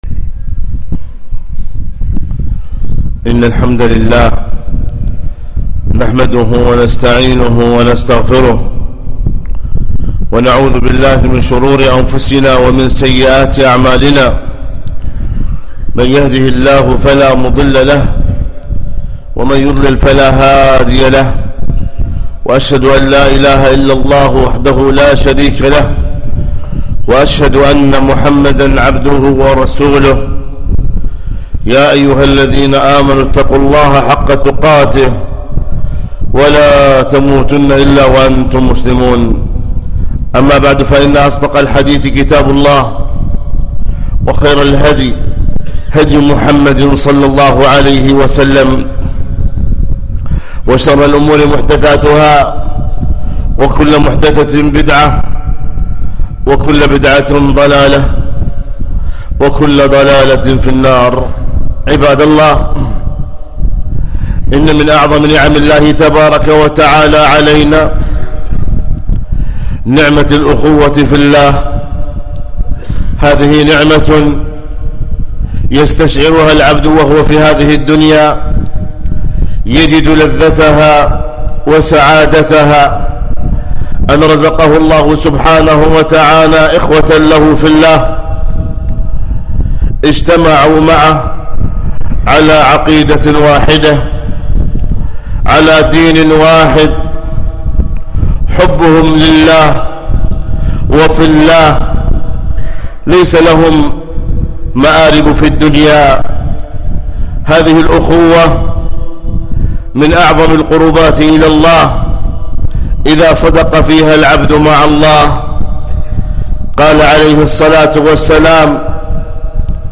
خطبة - ( إنما المؤمنون إخوة)